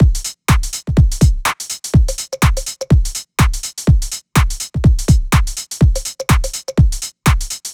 Index of /99Sounds Music Loops/Drum Loops/Dance